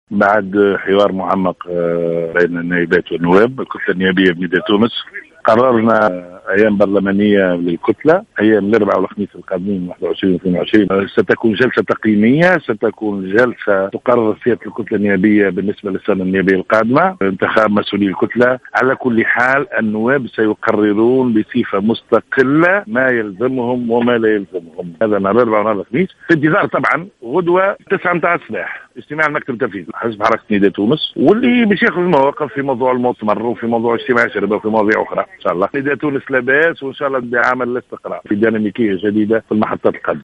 قال النائب عن حزب نداء تونس،منذر بالحاج علي اليوم،الخميس في تصريح ل"الجوهرة أف أم" إن الحزب "لاباس" وإن الأوضاع داخله تتجه نحو الاستقرار.